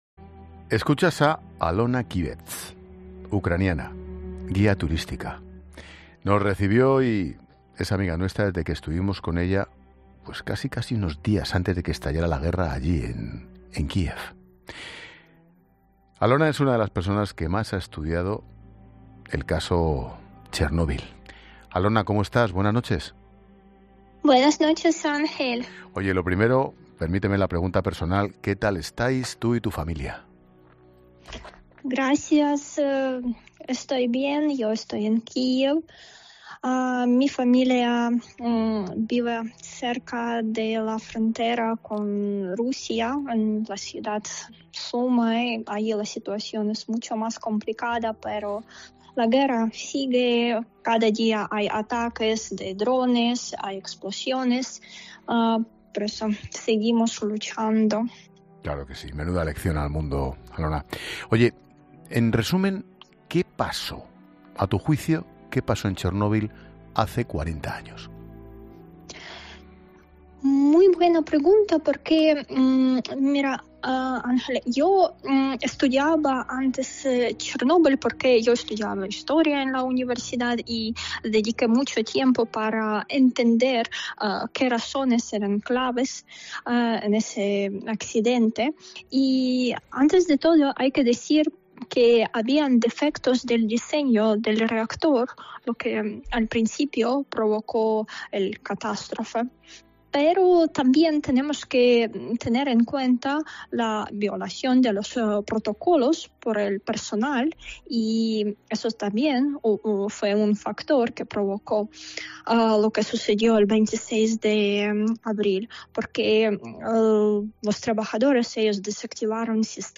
Ángel Expósito entrevista